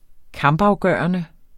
kampafgørende adjektiv Bøjning -, - Udtale [ ˈkɑmb- ] Betydninger 1.